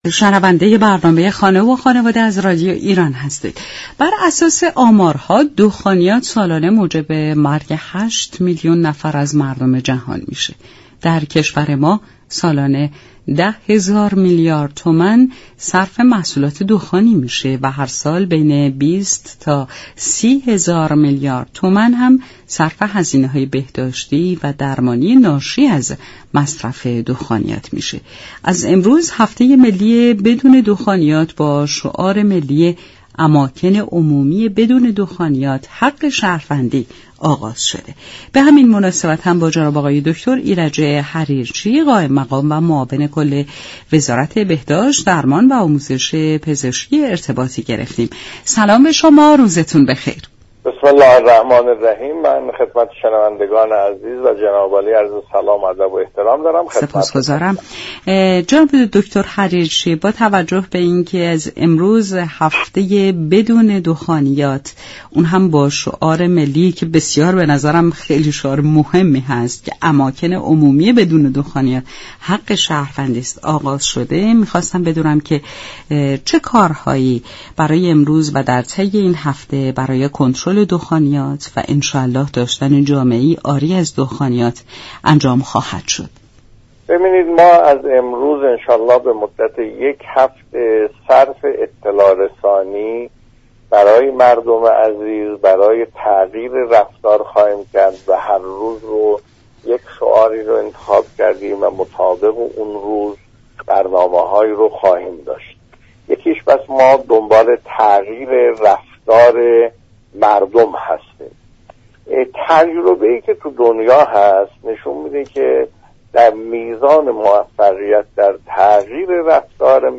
برنامه خانه و خانواده برای كسب اطلاعات بیشتر در این خصوص با ایرج حریرچی معاون كل وزارت بهداشت، درمان و آموزش پزشكی گفت و گو كرده است.